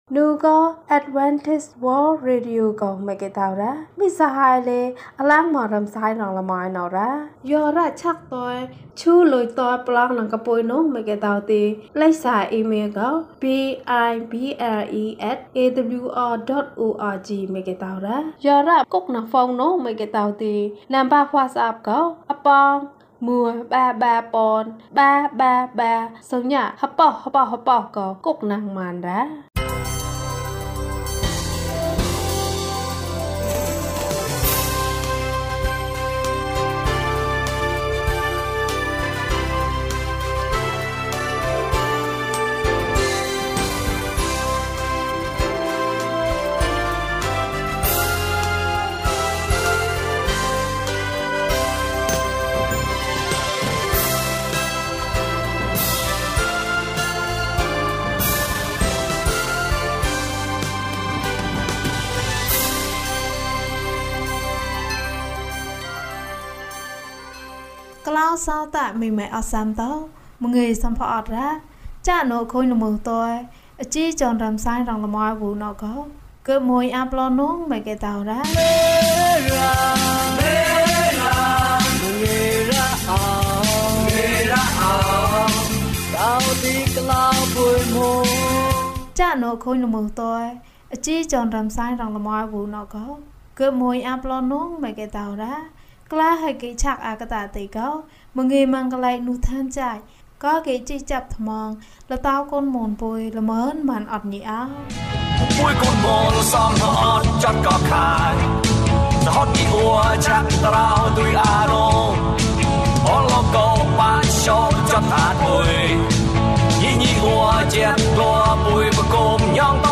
ယေရှုခရစ်သည် ကျွန်ုပ်၏အသက်ကို ကယ်တင်ပါ။၀၂ ကျန်းမာခြင်းအကြောင်းအရာ။ ဓမ္မသီချင်း။ တရားဒေသနာ။